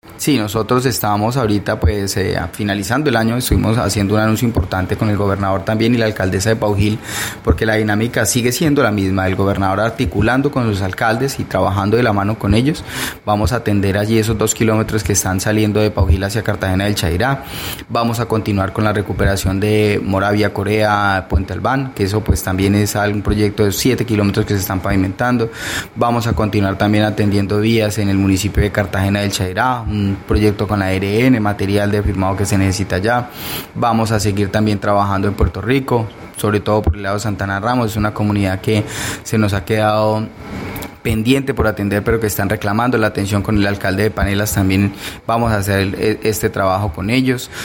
Herley Suarez Cuellar, secretario de infraestructura departamental, explicó que municipios como El Paujil, Cartagena del Chaira, Puerto Rico y San Vicente del Caguan, serán beneficiados con trabajos, especialmente en zonas rurales.